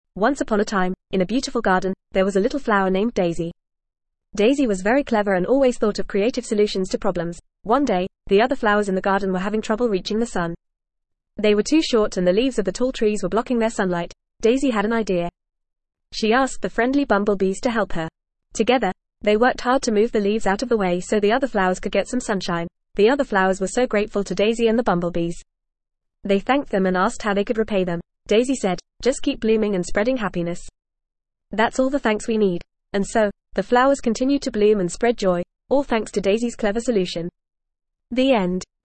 Fast
ESL-Short-Stories-for-Kids-FAST-reading-The-Clever-Flower.mp3